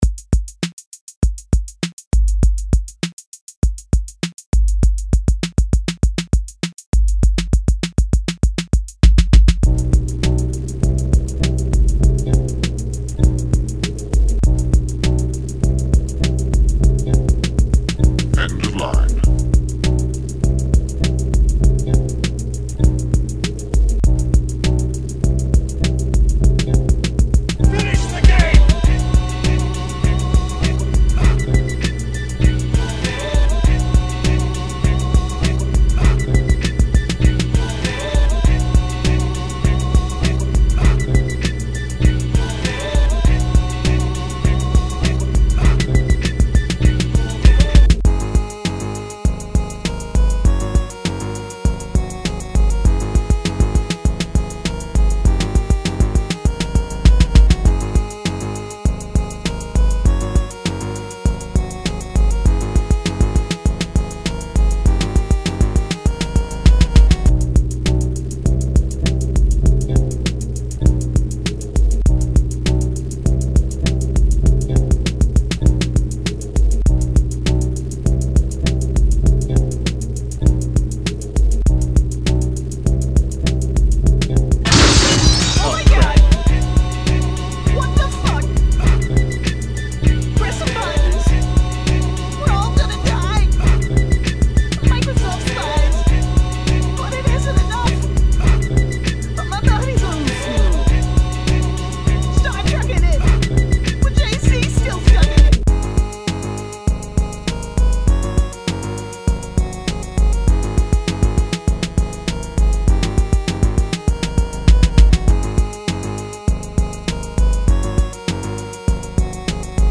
Instrumental -